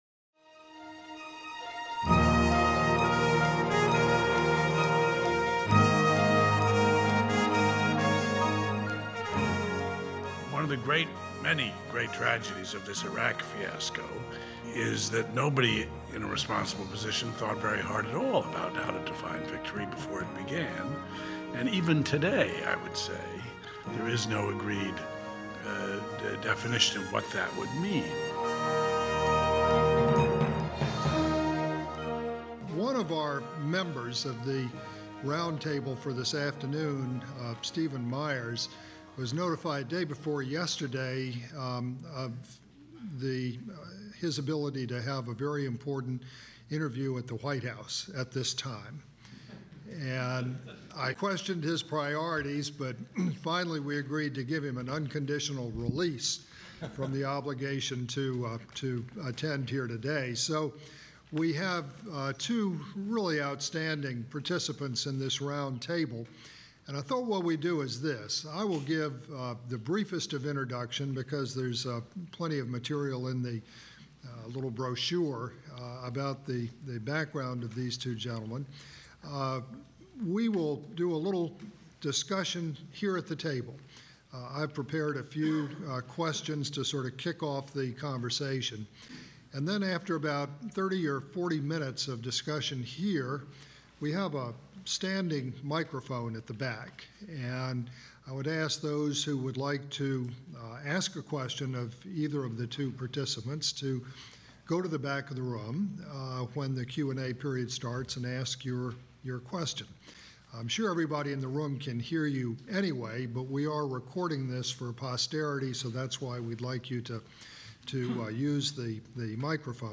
The Politics of Troop Withdrawal: The Case of Iraq--A Roundtable | Miller Center